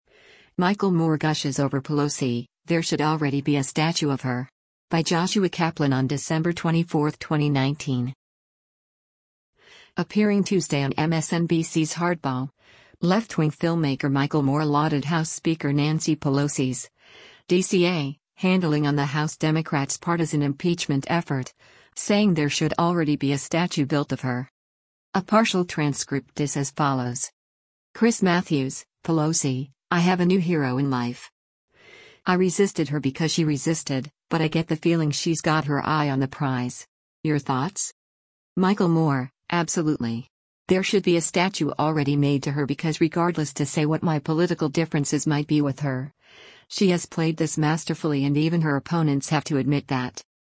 Appearing Tuesday on MSNBC’s Hardball, leftwing filmmaker Michael Moore lauded House Speaker Nancy Pelosi’s (D-CA) handling on the House Democrats’ partisan impeachment effort, saying there should already be a statue built of her.